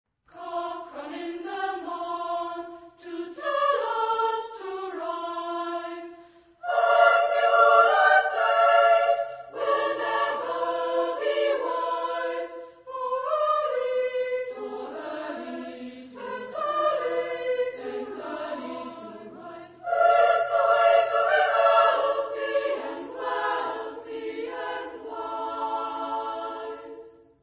Genre-Style-Forme : Cycle ; Pièce chorale ; Profane
Type de choeur : SSA  (3 voix égales de femmes )
Tonalité : si bémol majeur ; ré mineur